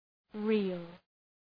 {rıəl, ri:l}